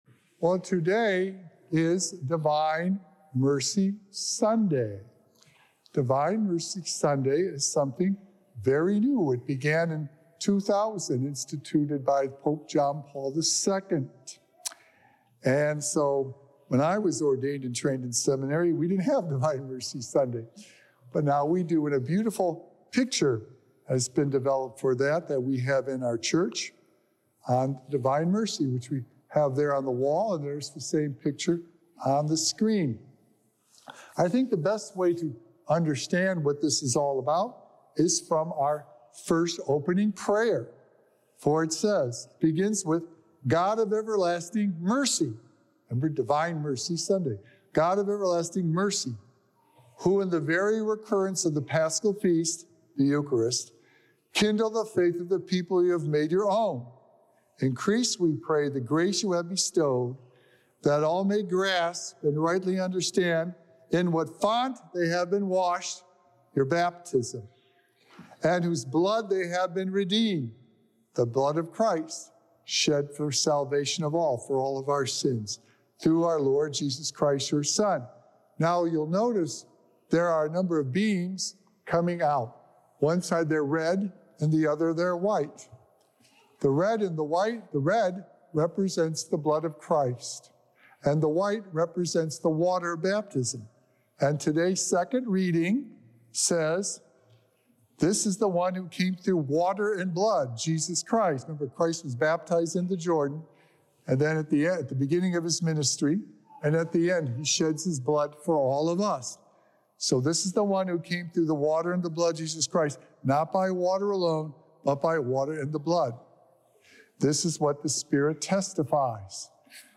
Sacred Echoes - Weekly Homilies Revealed
Recorded Live on Sunday, April 7th, 2024 at St. Malachy Catholic Church